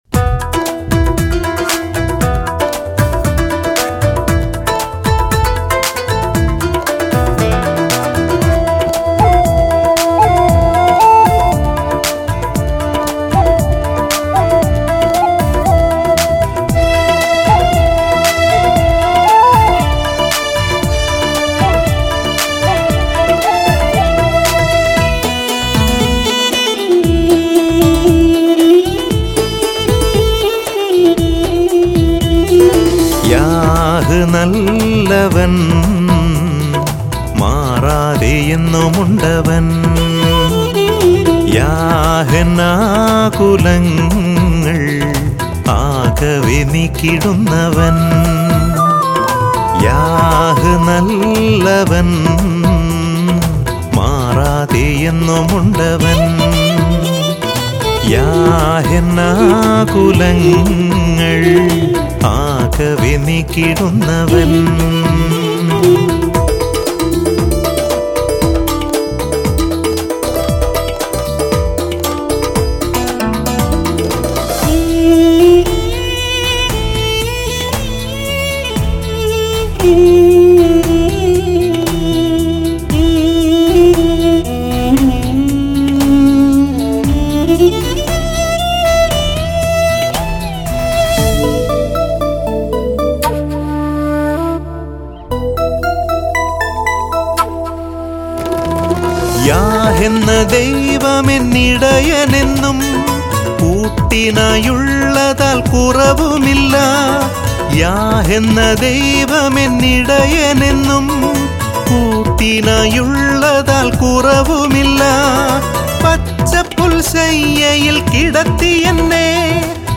Christian Devotional Songs & Video Albums